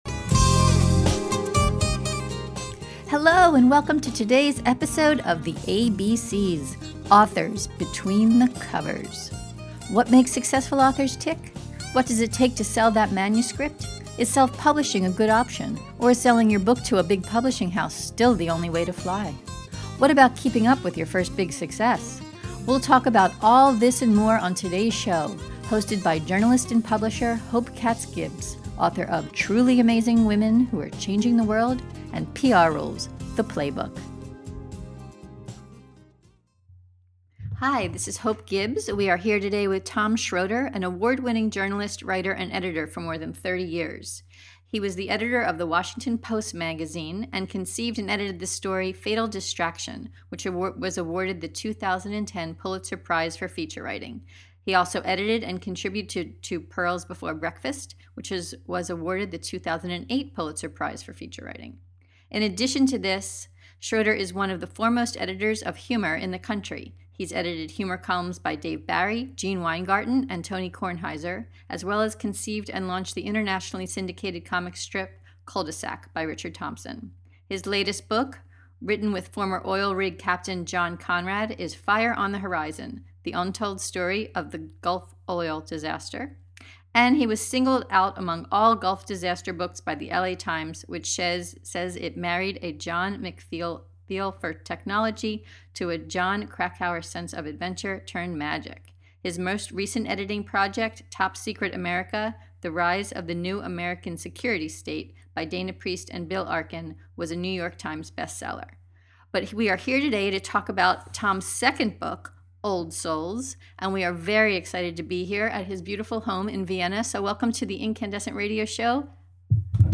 You’ll learn about that, and more, in this podcast interview with journalist, author and one of the foremost editors of humor in the country.